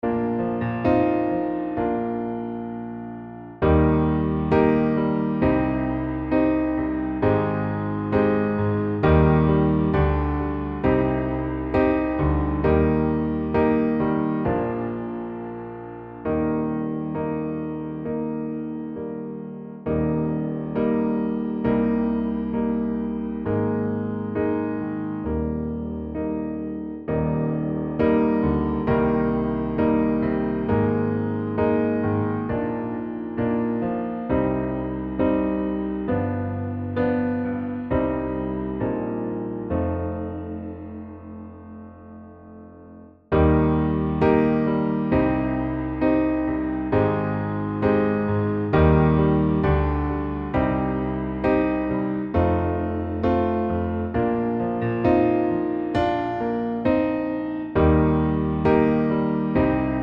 Country (Female)